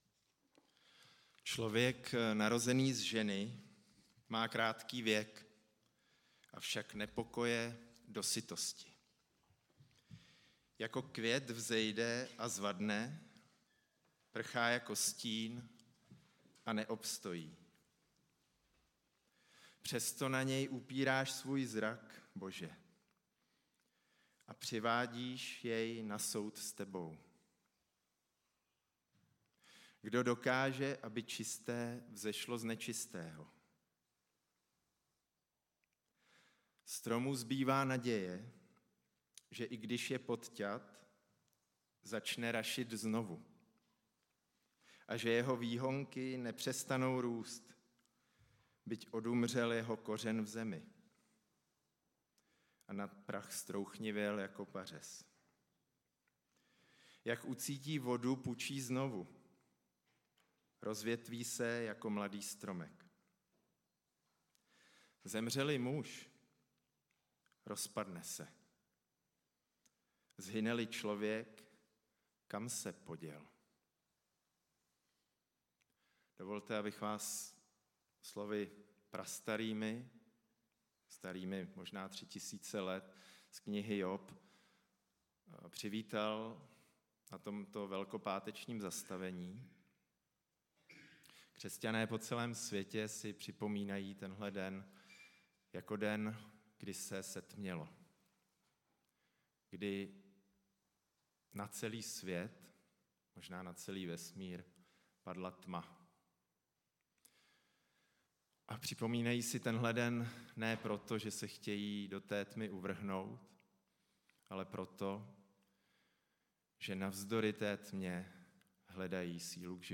Velkopáteční bohoslužba